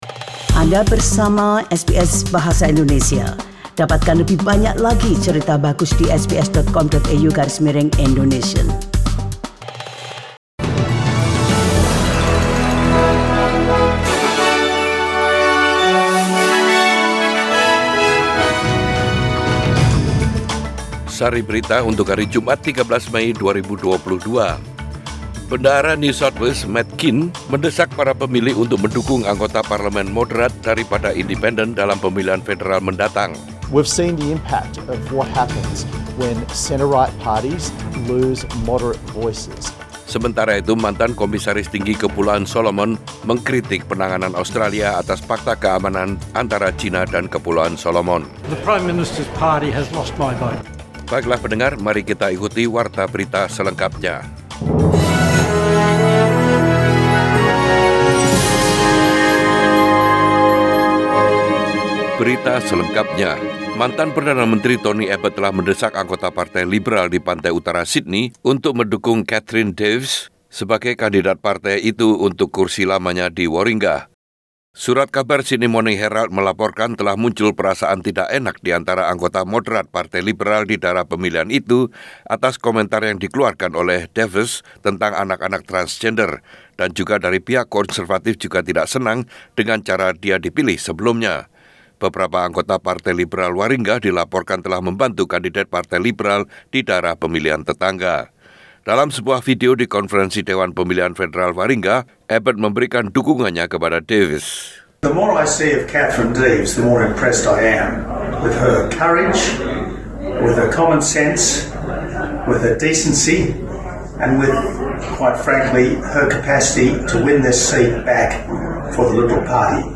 Warta Berita Radio SBS Program Bahasa Indonesia.